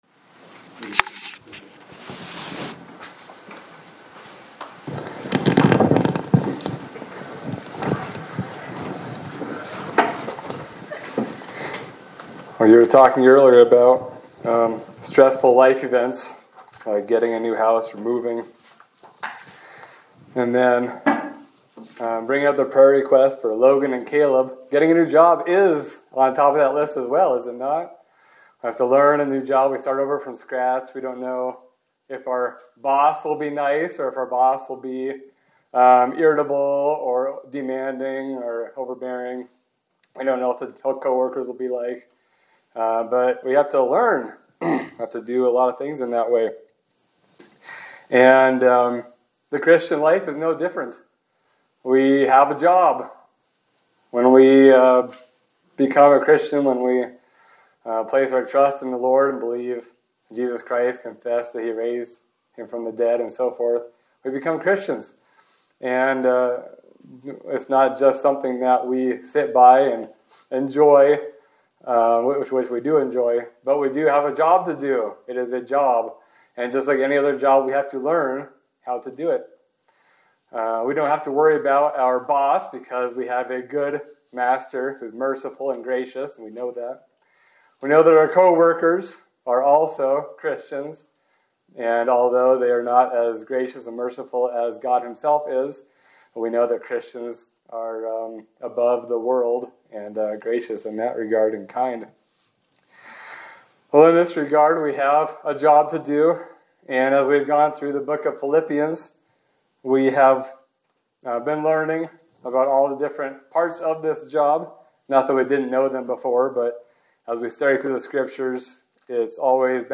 Sermon for April 12, 2026
Service Type: Sunday Service